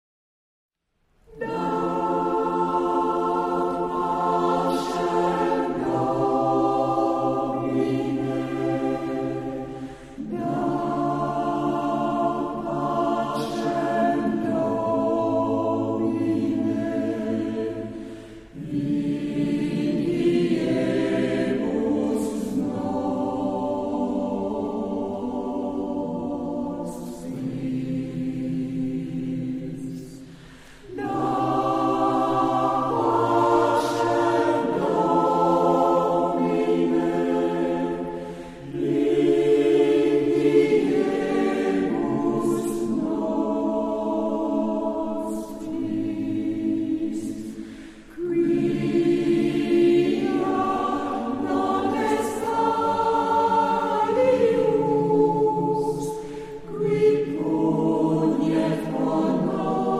Epoque: 20th century
Genre-Style-Form: Motet ; Sacred
Type of Choir: SATB  (4 mixed voices )
Tonality: G major